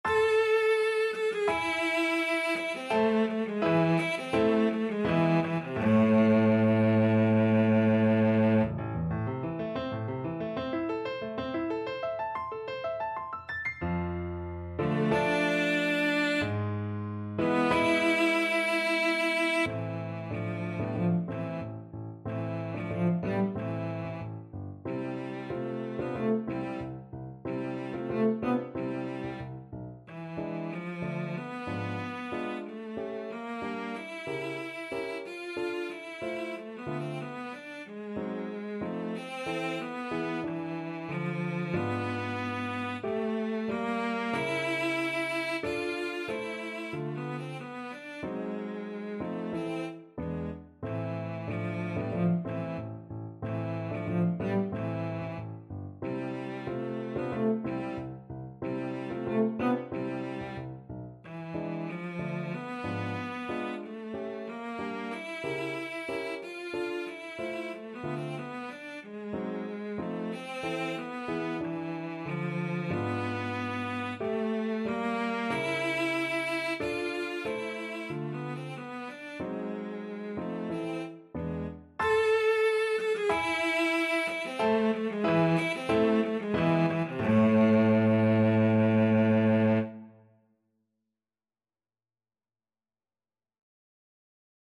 Classical Grieg, Edvard Piano Concerto Mvt 1 theme Cello version
A minor (Sounding Pitch) (View more A minor Music for Cello )
4/4 (View more 4/4 Music)
Allegro molto moderato = 84 (View more music marked Allegro)
Classical (View more Classical Cello Music)